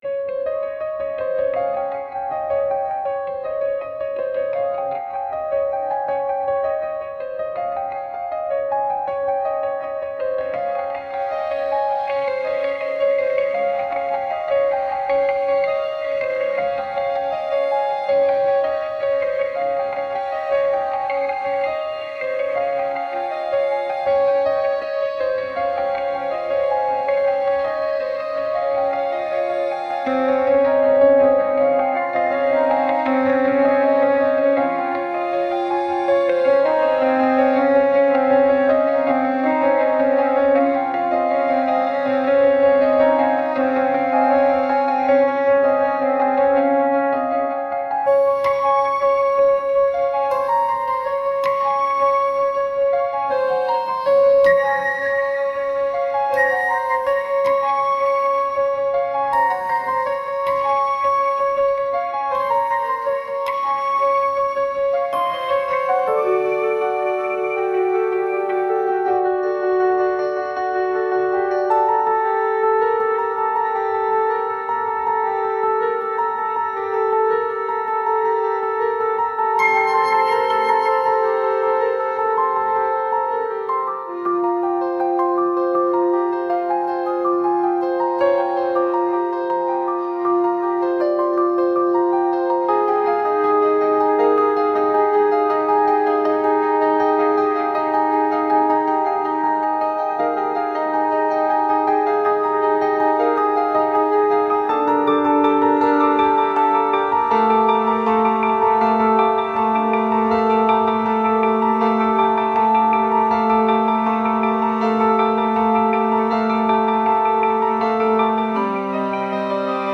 mood_-graceful-delicateinstruments_piano-.mp3